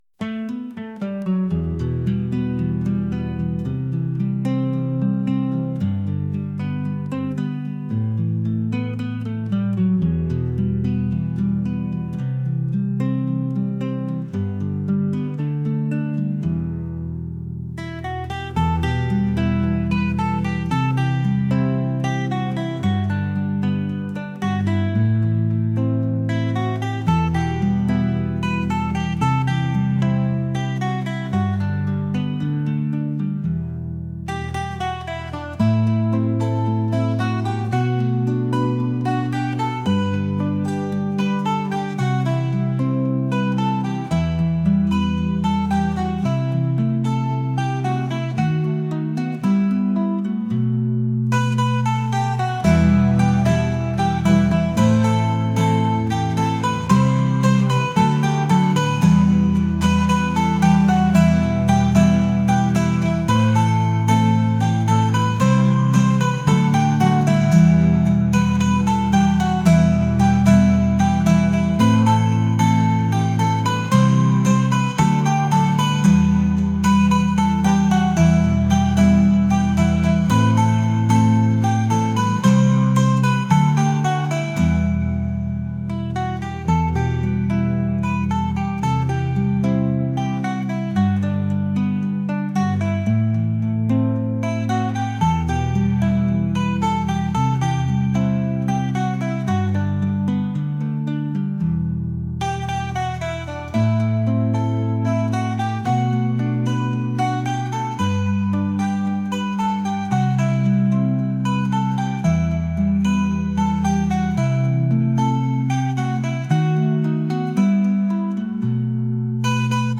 folk | acoustic | ambient